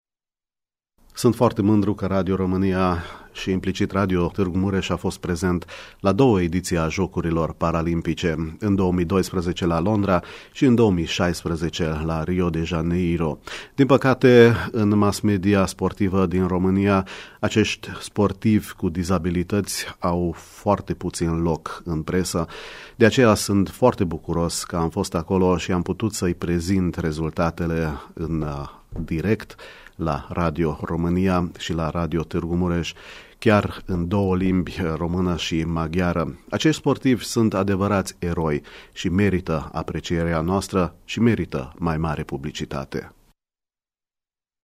Microfonul Radio România a surprins performanța a două olimpiade paralimpice.